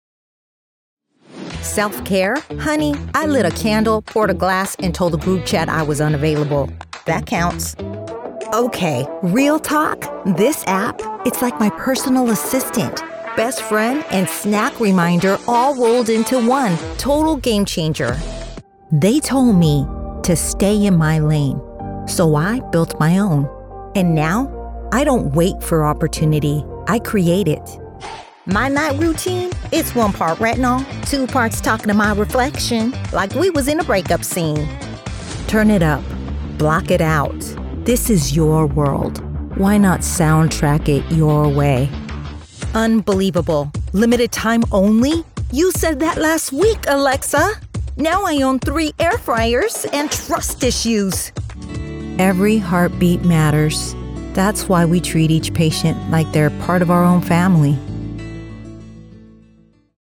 American Voice Over Talent
Adult (30-50)
Our voice over talent record in their professional studios, so you save money!